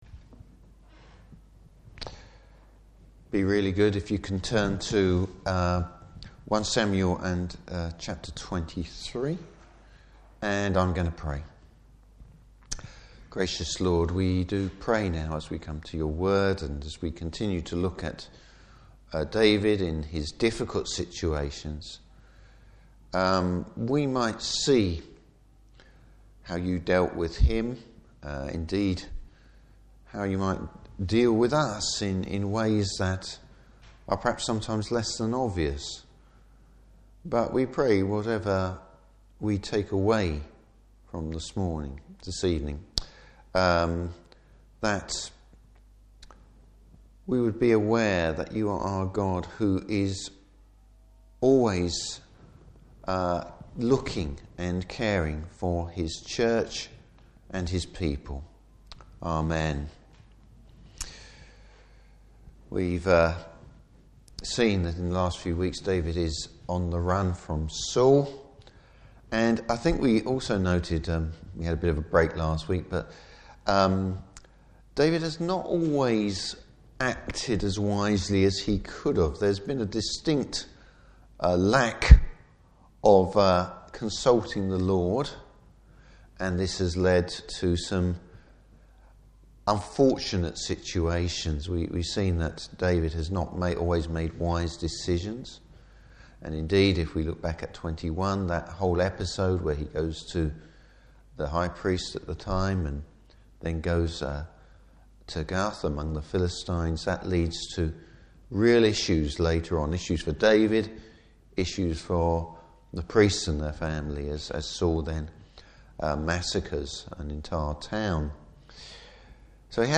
Service Type: Evening Service David’s on the run again, but he’s looking to the Lord!